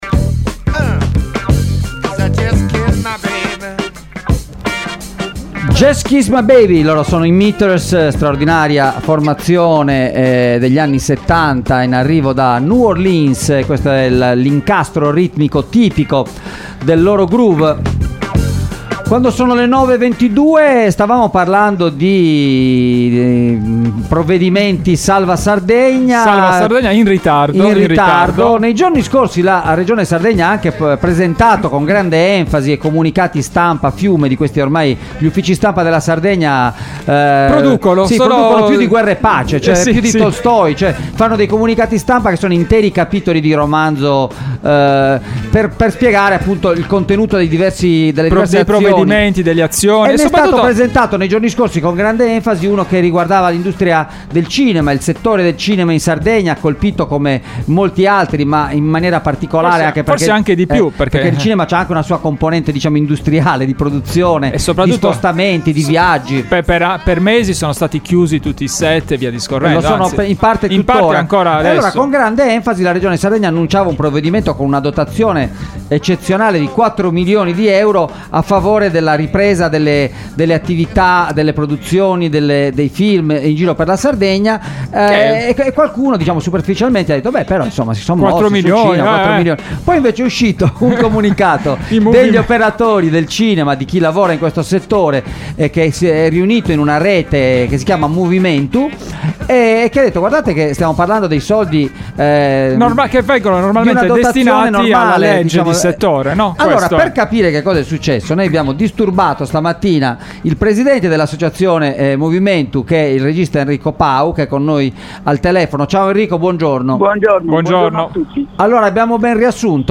è intervenuto ai microfoni di Extralive mattina